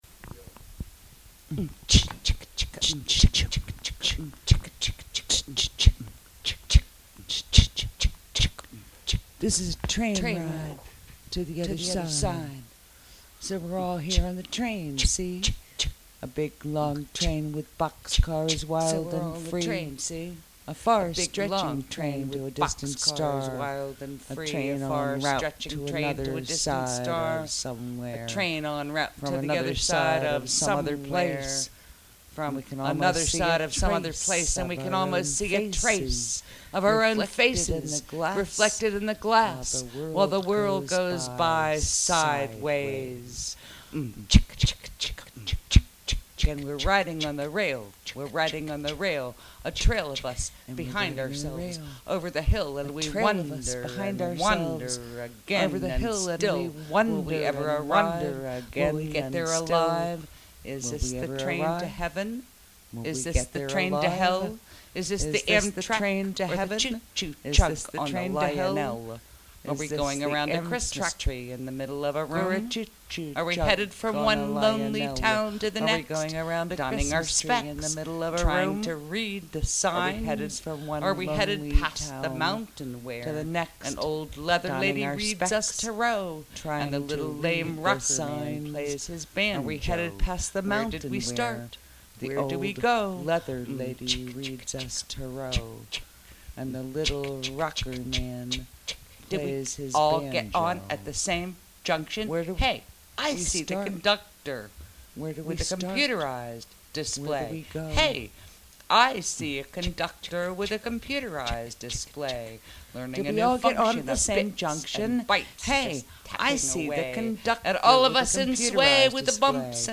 Train ride to the other side spoken word